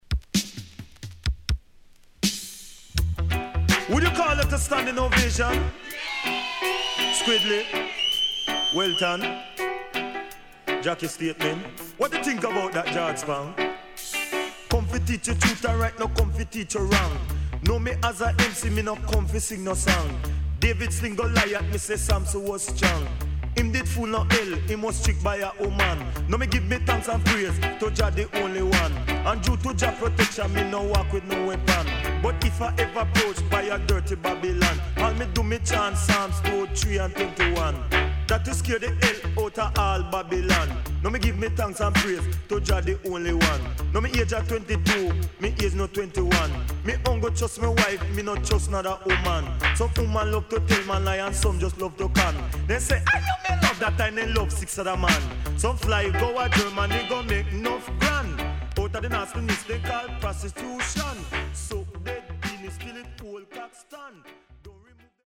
HOME > LP [DANCEHALL]
SIDE A:少しチリノイズ入ります。